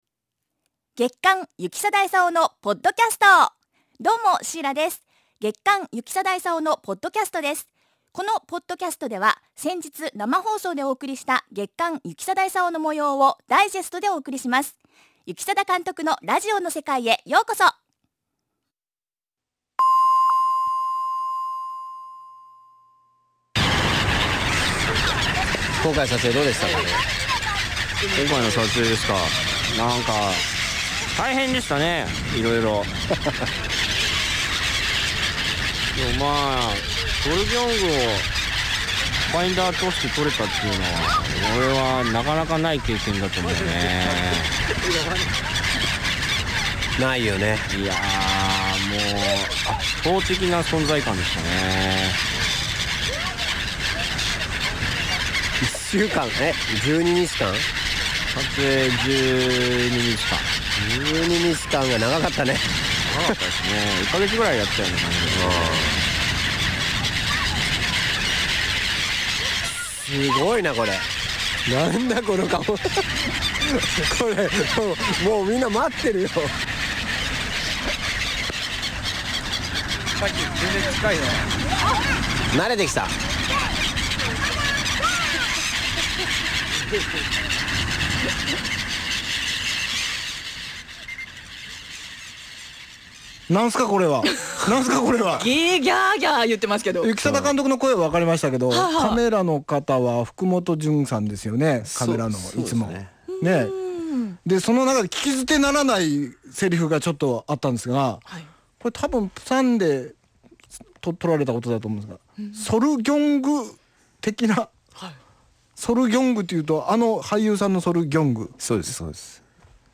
FMラジオ局・エフエム熊本のポッドキャスト。